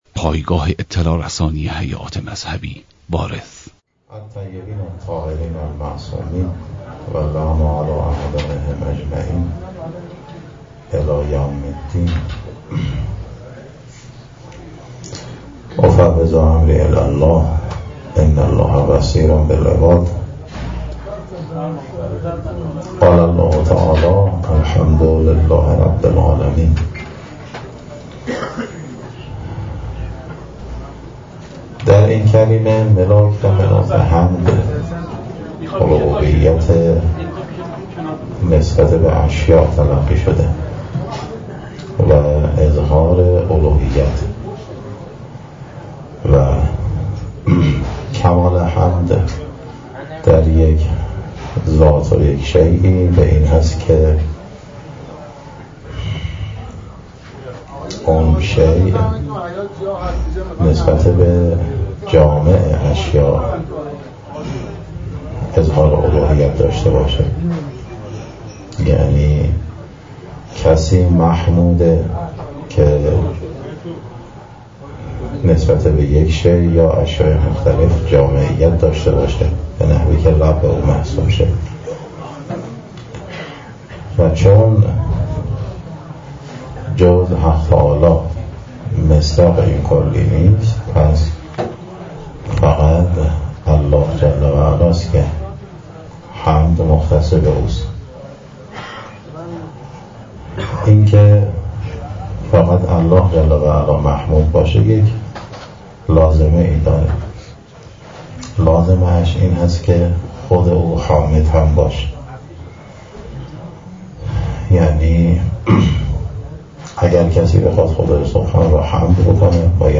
بزم روضه در شب شهادت امام سجاد علیه السلام در حسینیه بیت الزهرا(س)